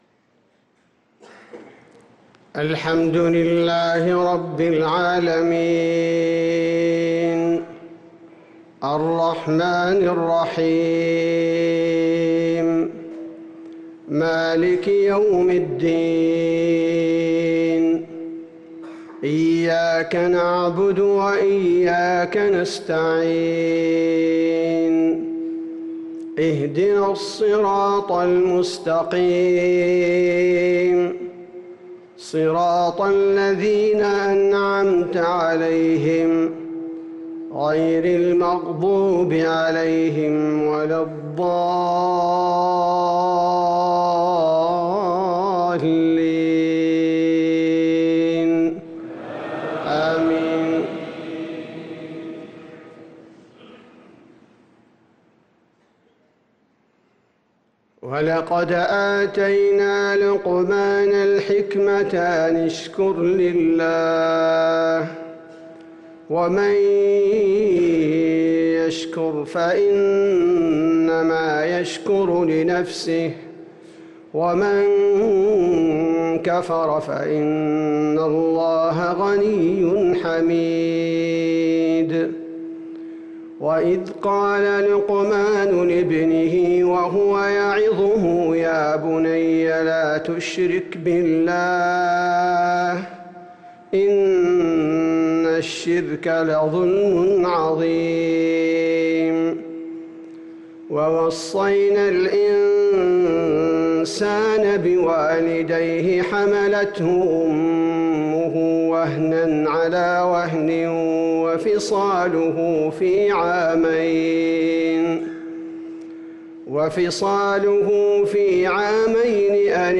صلاة العشاء للقارئ عبدالباري الثبيتي 18 جمادي الأول 1445 هـ
تِلَاوَات الْحَرَمَيْن .